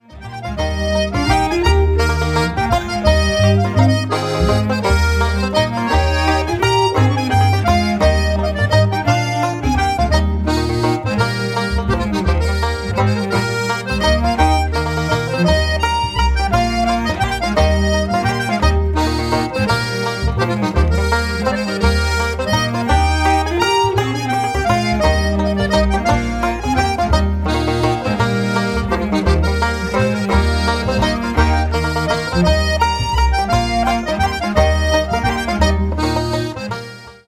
piano
Bodhran
The banjo and button accordion make a bold, powerful pair.